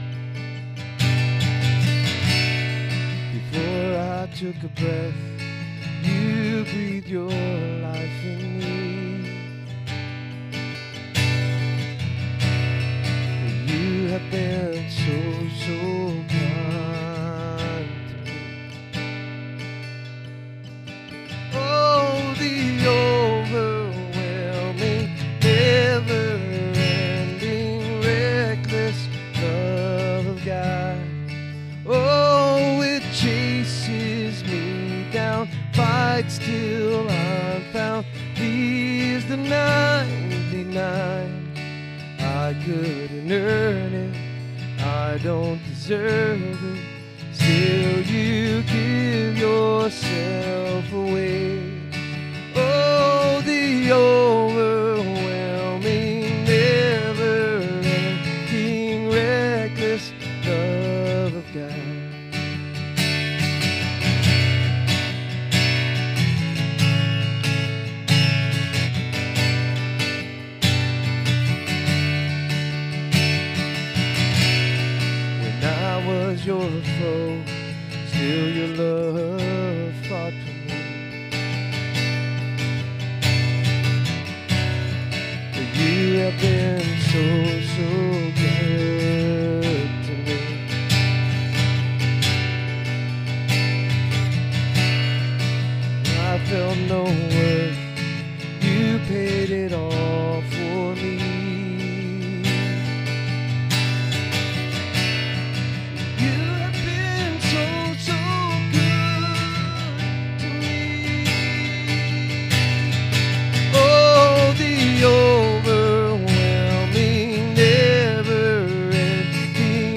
SERMON DESCRIPTION This sermon explores the warning of Hosea that God’s people often chase “the wind” by placing their trust in false saviors, worldly alliances, and empty pursuits instead of resting in God’s covenant love.